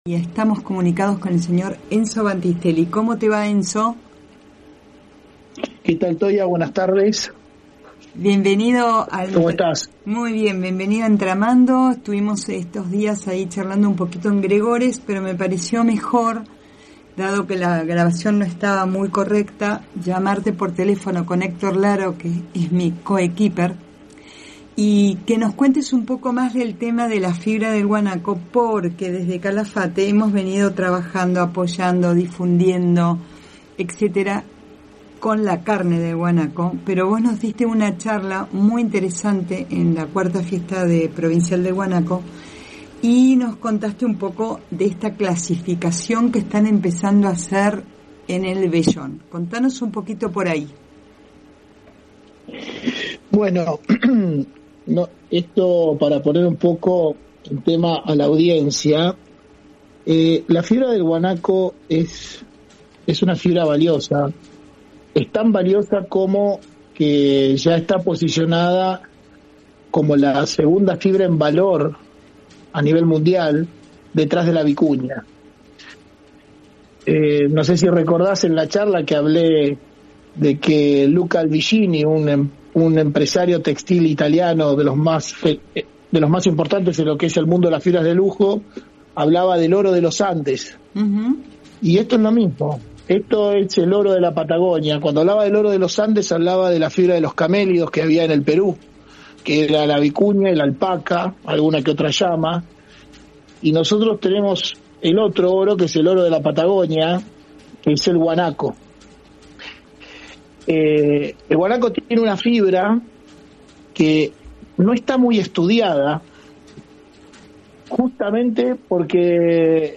desveló en una entrevista al programa 'Entramando' de FM Dimensión (Calafate) los secretos detrás de la fibra del guanaco. Conoce su potencial en el mercado internacional y los desafíos de su aprovechamiento sostenible.